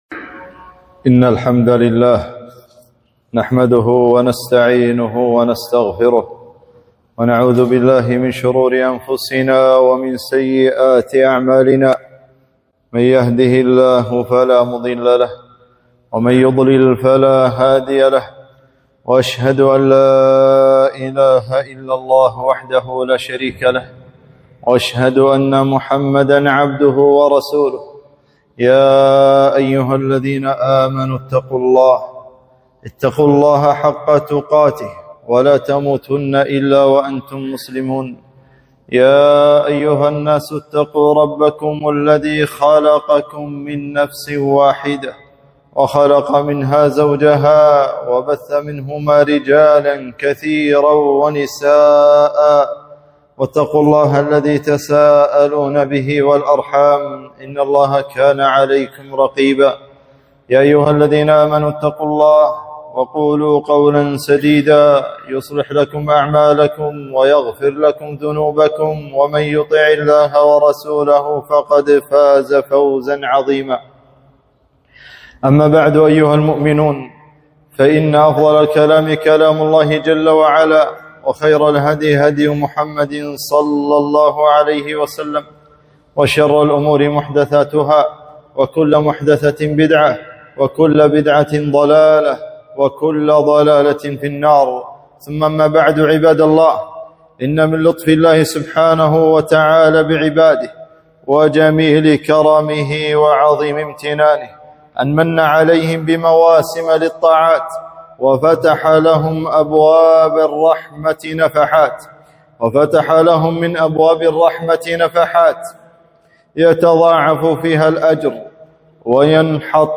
خطبة - الاستعداد لرمضان